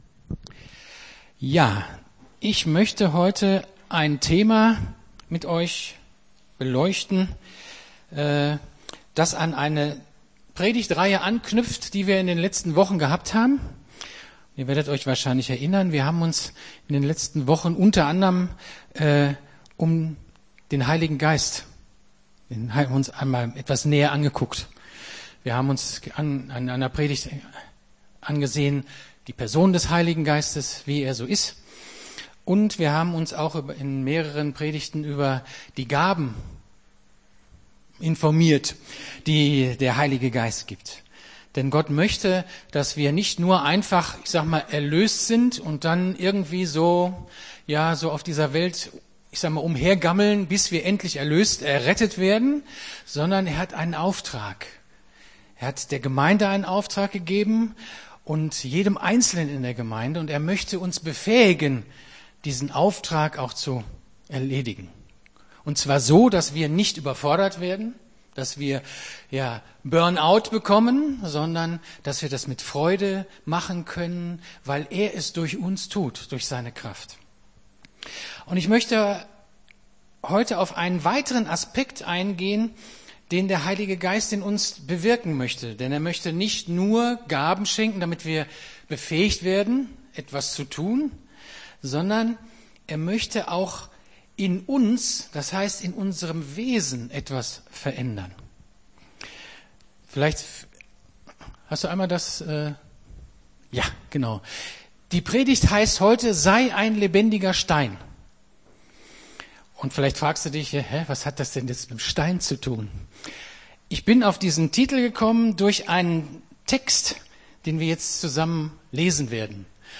Gottesdienst 22.10.23 - FCG Hagen